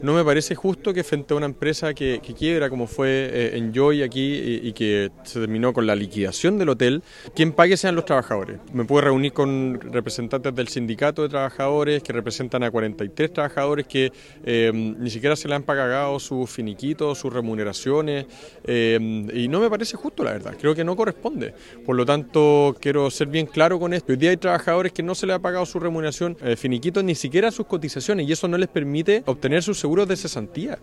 Estas declaraciones las entregó a Radio Bío Bío, donde confirmó que se reunió con el sindicato que representa a 43 trabajadores del hotel.
alcalde-puerto-varas.mp3